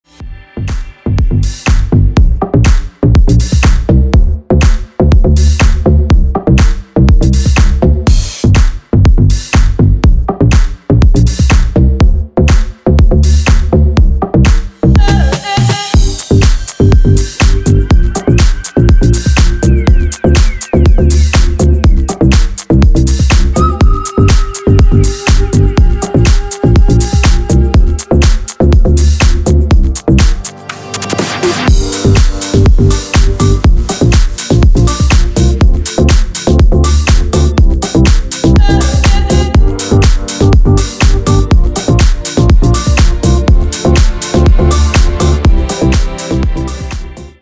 • Качество: 320, Stereo
ритмичные
remix